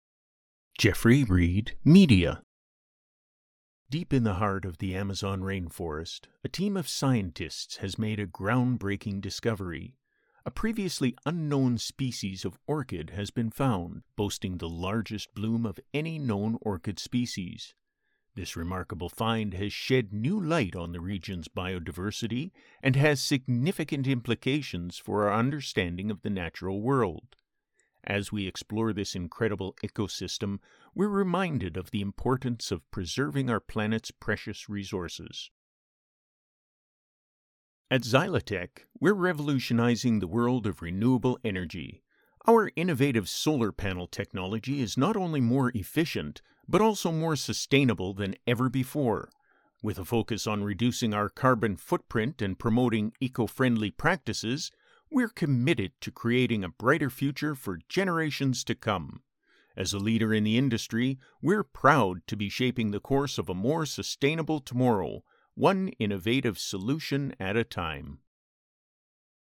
Narration Demo
Documentary and corporate narration samples
narration.mp3